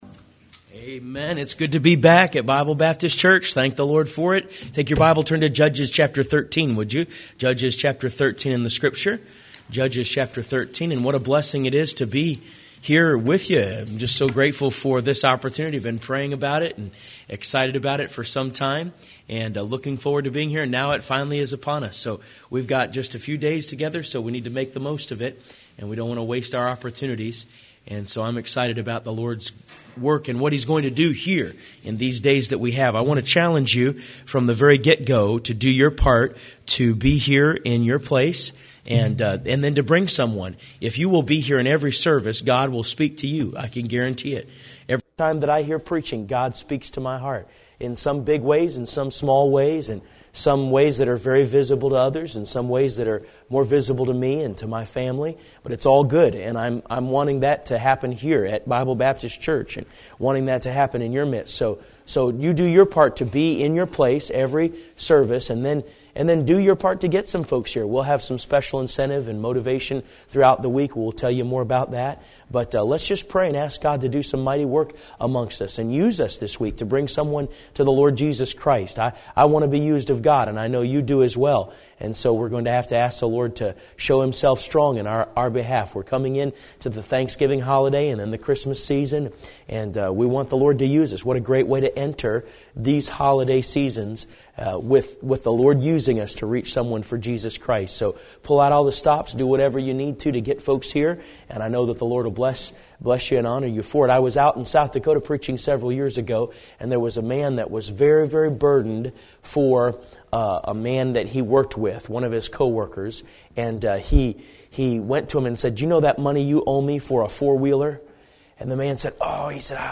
11/18/12 Fall Revival Service 1 Samson – Bible Baptist Church